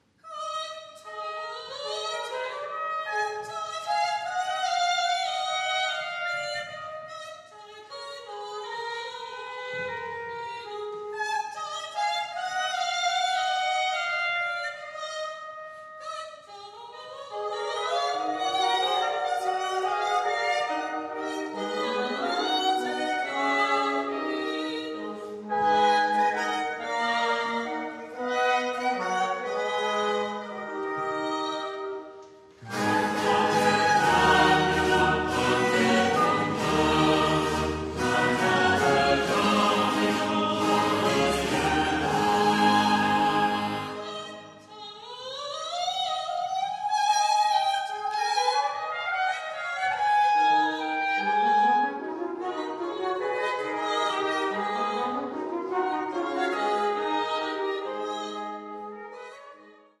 auf historischen Instrumenten